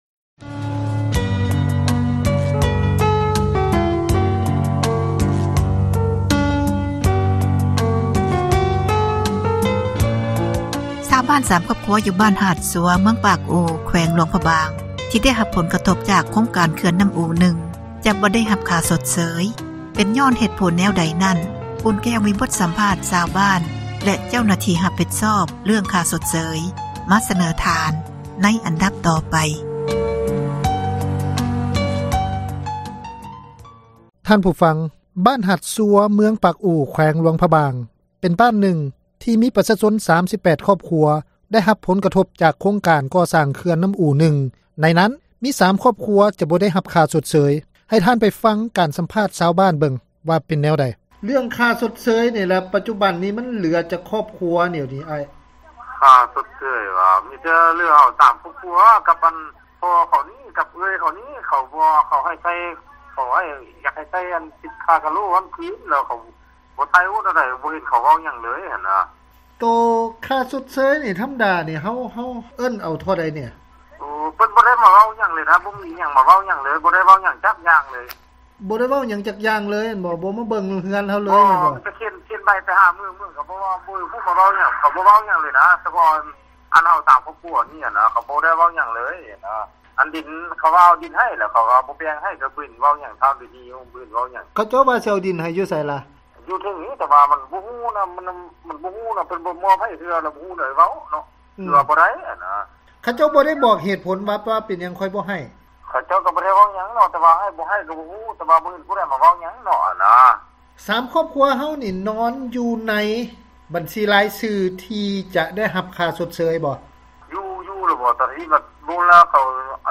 ສໍາພາດຊາວບ້ານຜູ້ ບໍ່ໄດ້ ຄ່າຊົດເຊີຍ